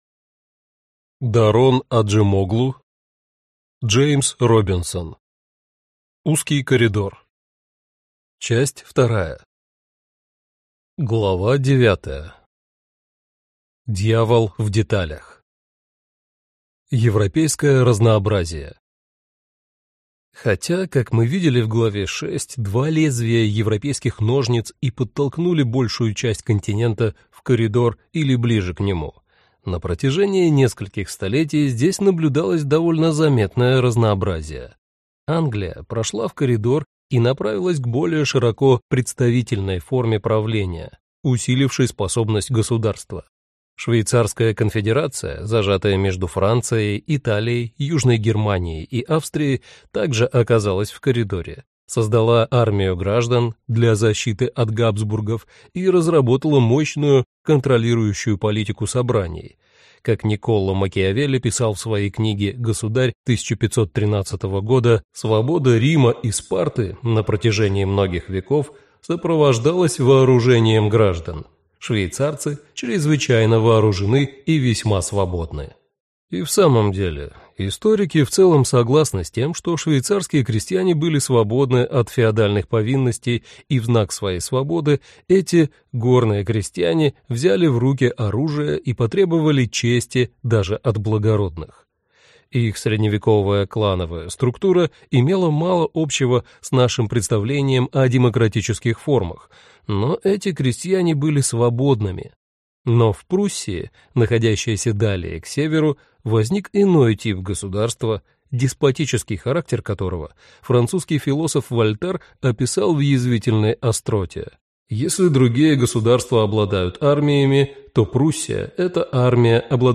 Аудиокнига Узкий коридор. Часть 2 | Библиотека аудиокниг